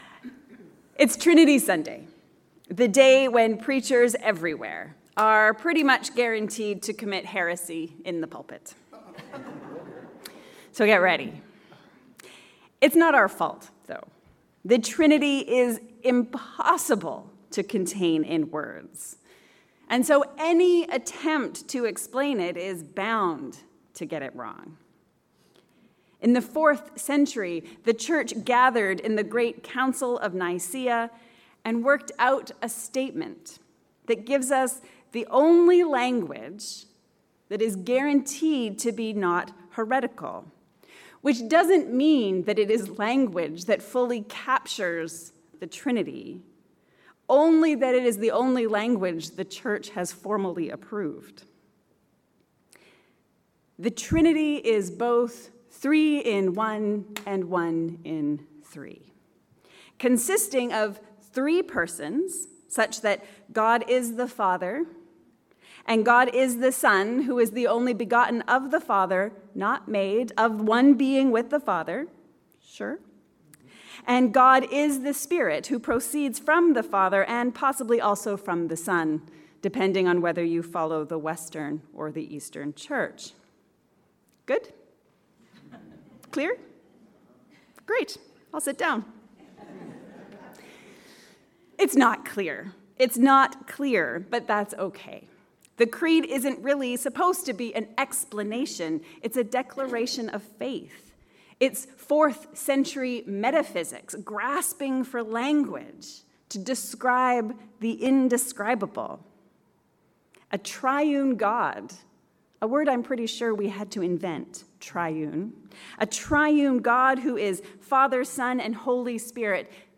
Three-in-One and One-in-Three…??? A sermon for Trinity Sunday